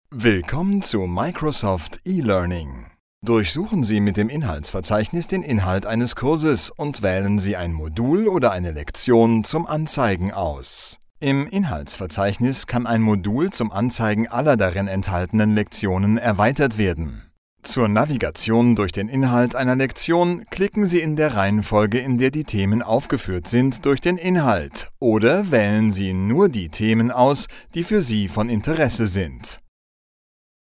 DE DP EL 01 eLearning/Training Male German